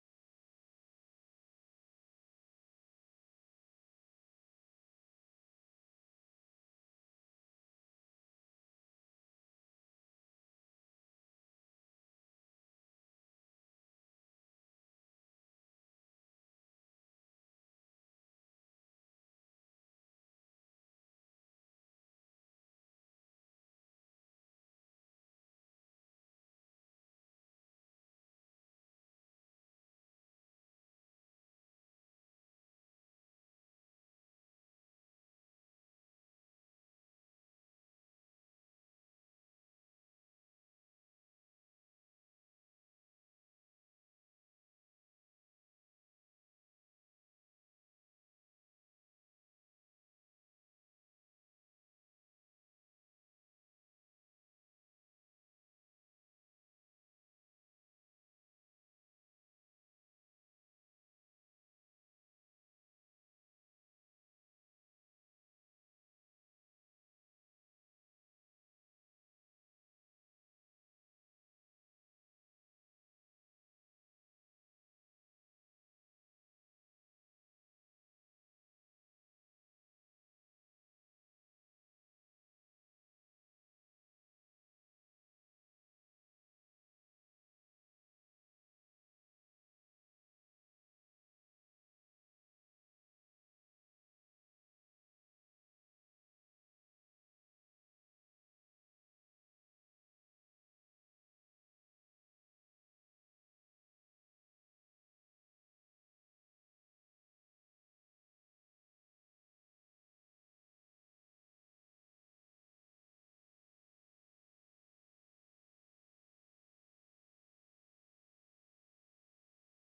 La Dra. Mariàngela Vilallonga imparteix la seva darrera lliçó, dedicada a l’anàlisi de les estructures temàtiques de les Bucòliques de Virgili, que posa en relació amb correlacions numèriques significatives. Segueix reivindicant la vigència dels clàssics grecs i llatins en la literatura de tots els temps.